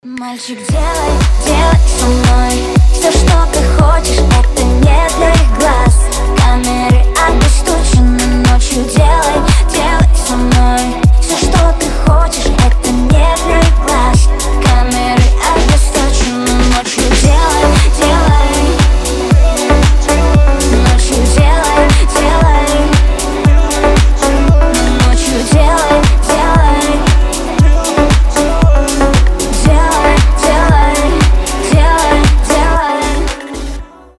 • Качество: 320, Stereo
поп
чувственные